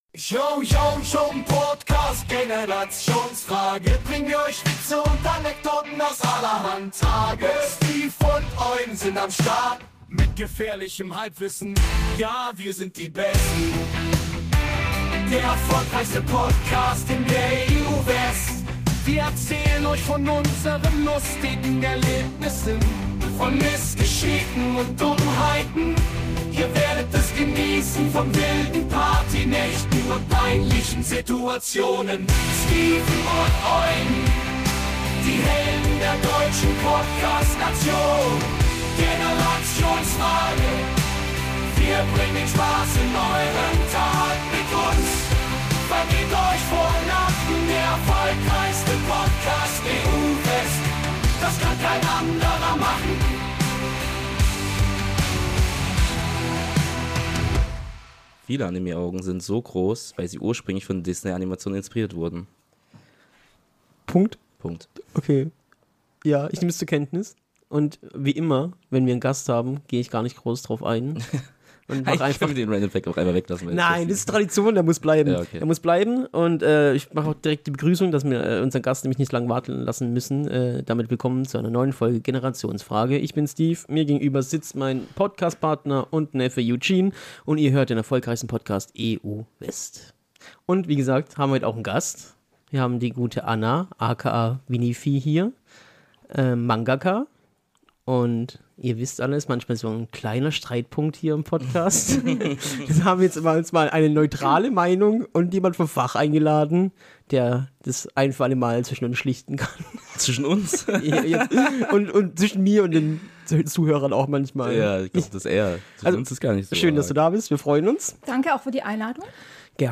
Gästezeit!